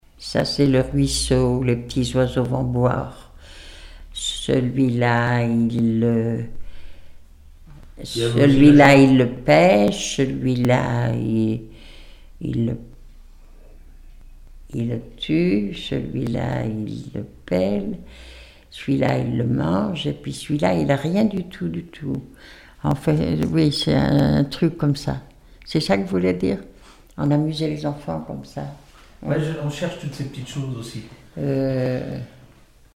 formulette enfantine : jeu des doigts
Témoignages et musiques
Pièce musicale inédite